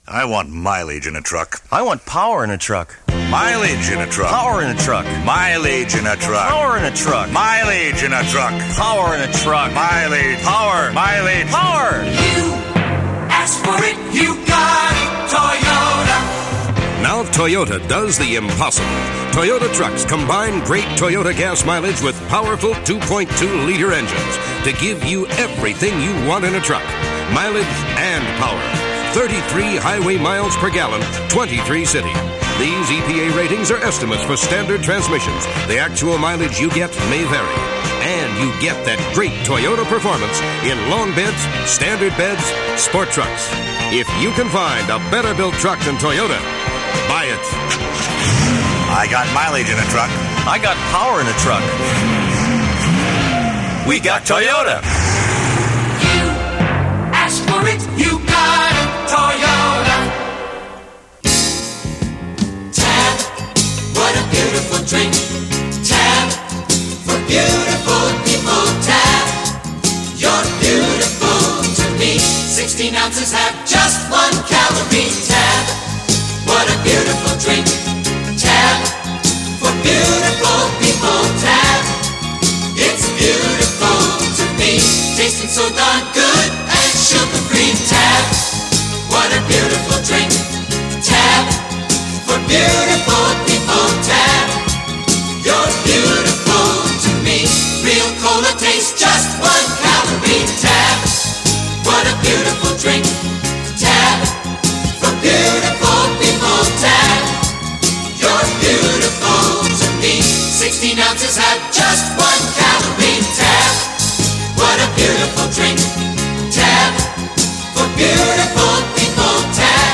People have often said that the 60s really didn’t end until 1972, and you can believe that, just by listening to the 1972 commercial for Annie Greensprings Wine – very groovy, laid back and mellow – buzz-words for the early 70s.